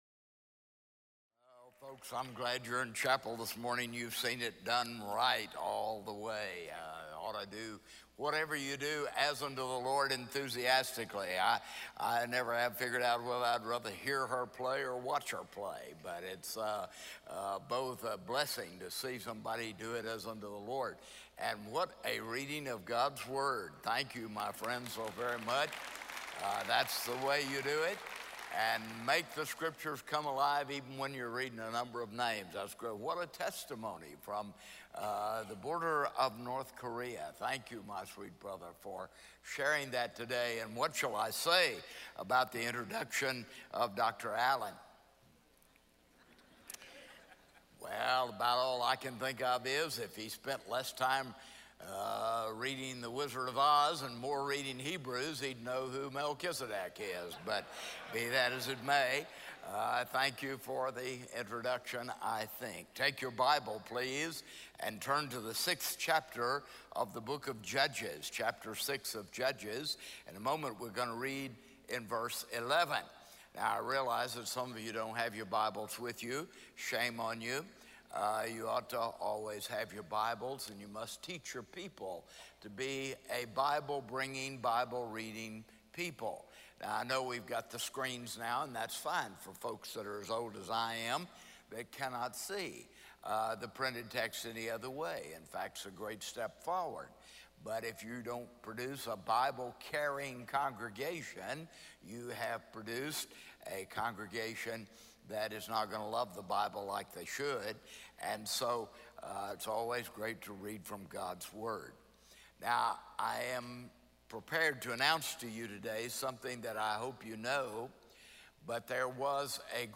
SWBTS Chapel Sermons